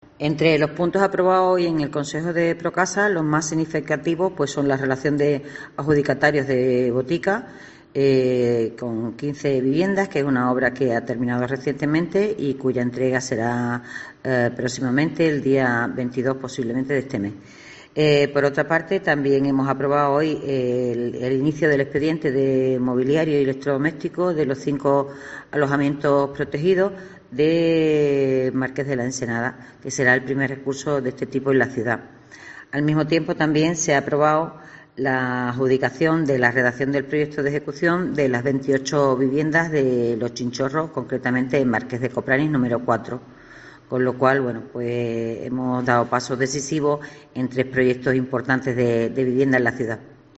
Eva Tubío, concejala de Vivienda del Ayuntamiento de Cádiz